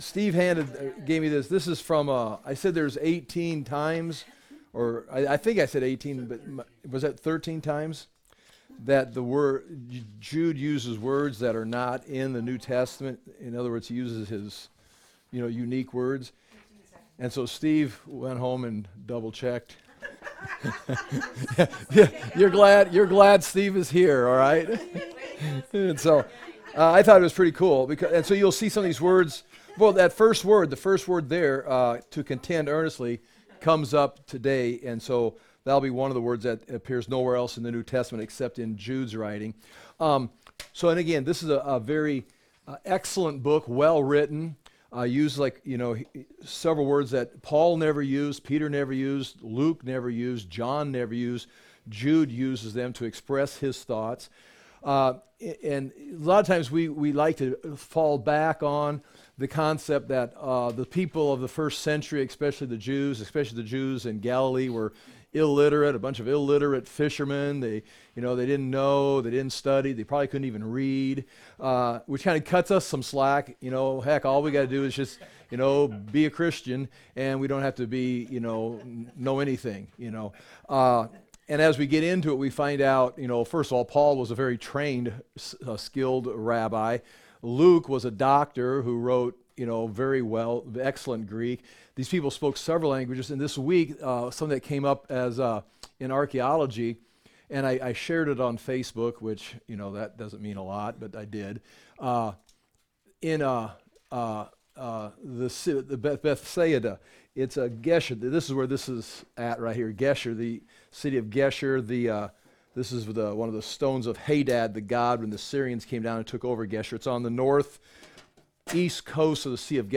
Jude - verse by verse Bible teaching audio .mp3, video, notes, maps, lessons for the Book of Jude